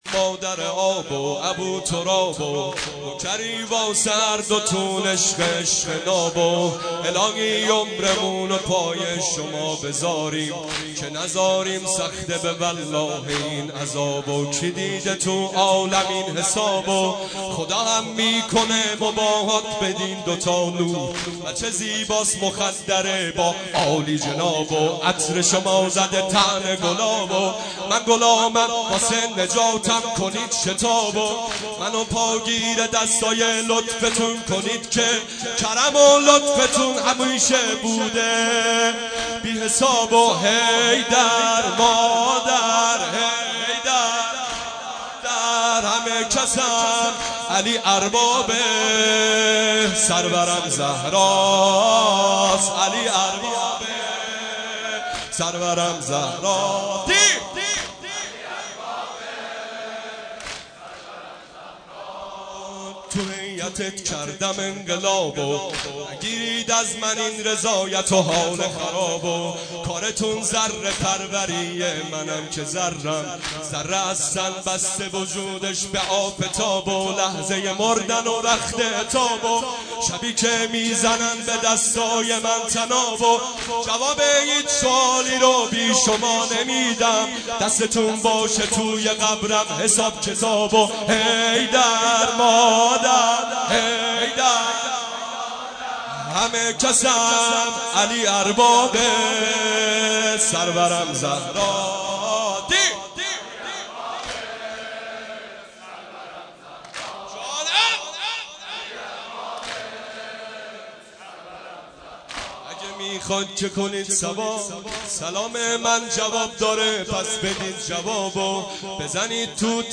واحد | عشق ناب
مداحی جدید
واحد حماسی